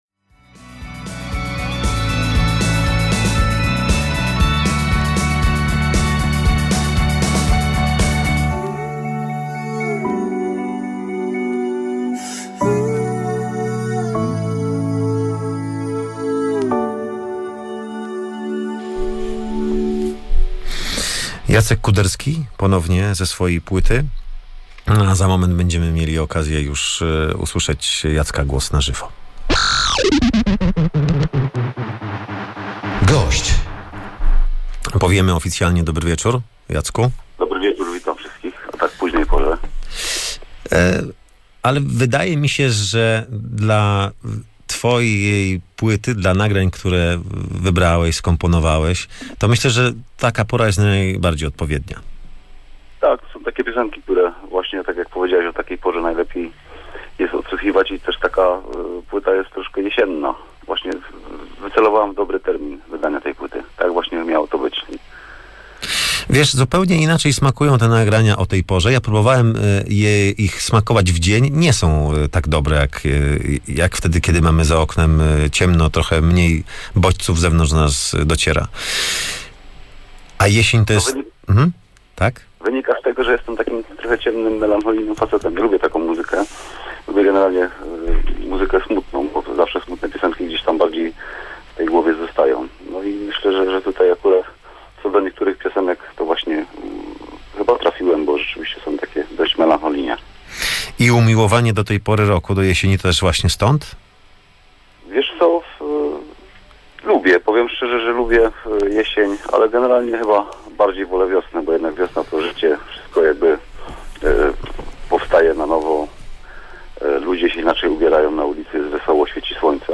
Rozmowa z Jackiem Kuderskim - Radio Łódź